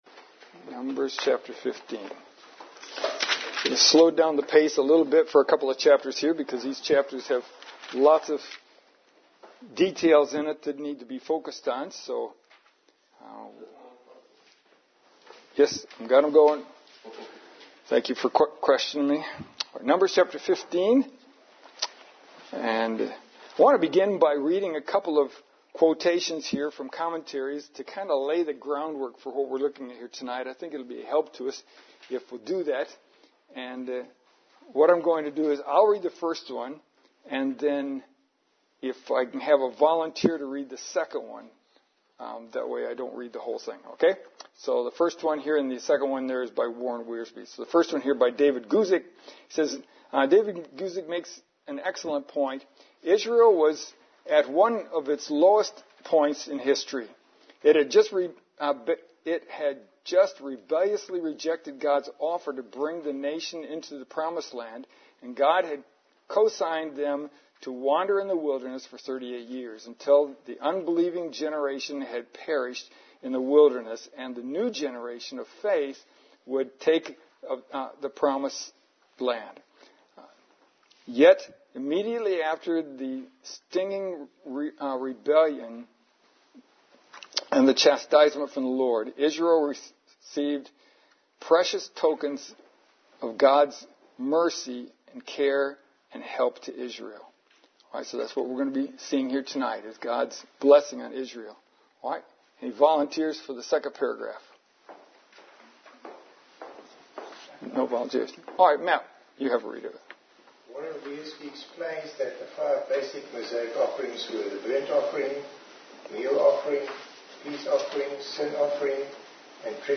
NUMBERS 1 November 2023 Lesson: 9 Numbers 15 The Failure of Israel in the Wilderness Once again, the main outline is from the Open Bible, slightly edited.